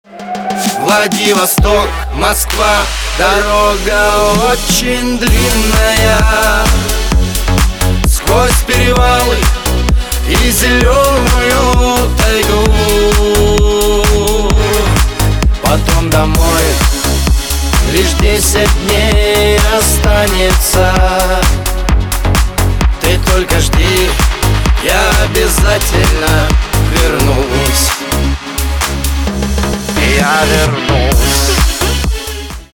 поп
битовые